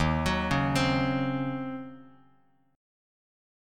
D#m13 chord